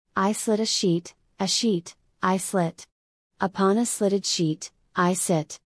This tongue twister teaches you the sl consonant cluster and the difficult sh sound, like in “sheet.”
You also get to practice the vowel sound ee, like in “sheet” and the i sound as in “sit” and “slit.”